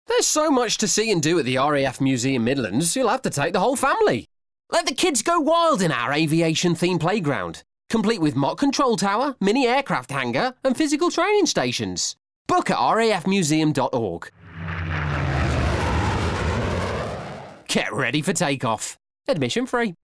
The sonic ident reflected the history and longevity of the RAF – by gradually morphing the sound of a spitfire-esque plane into that of a modern fighter jet.